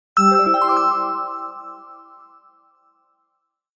Index of /phonetones/unzipped/Nokia/5300-XpressMusic-NewFW/Alert tones
Message 5.aac